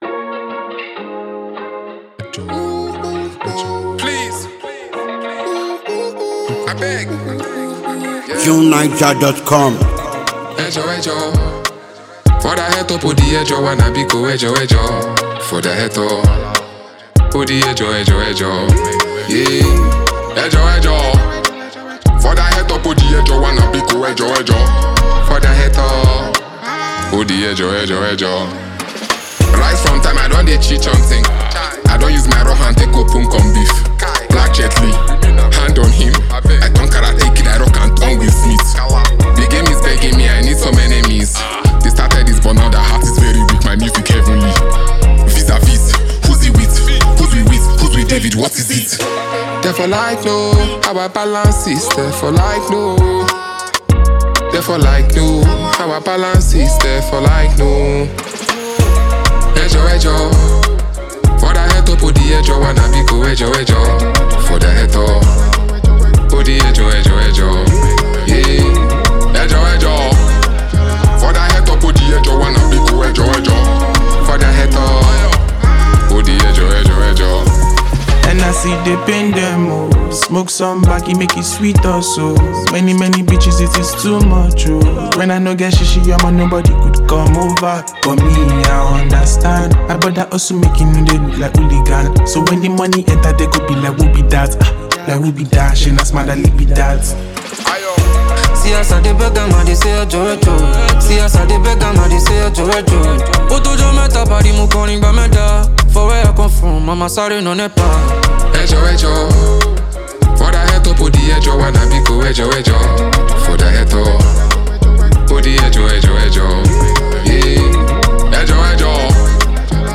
Nigerian rapper
Nigerian singer